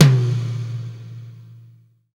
TOM XTOMM0KR.wav